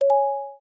Cam_Stop.ogg